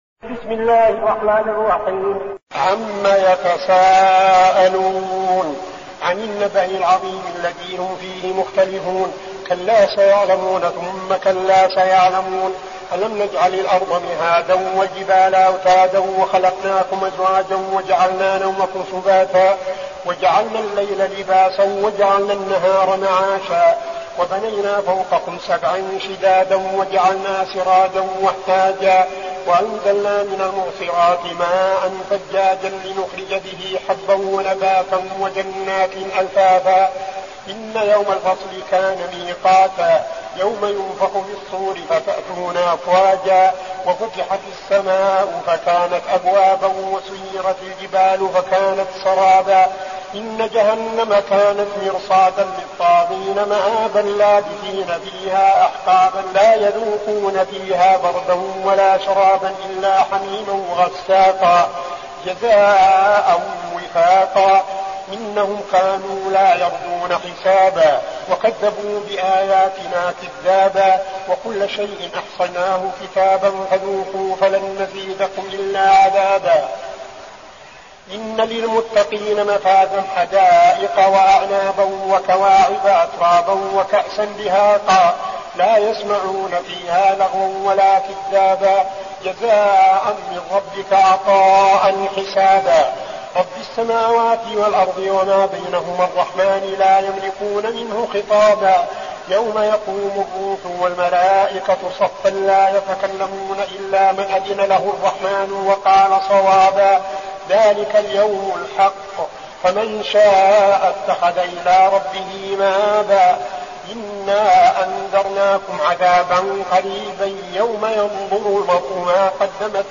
المكان: المسجد النبوي الشيخ: فضيلة الشيخ عبدالعزيز بن صالح فضيلة الشيخ عبدالعزيز بن صالح النبأ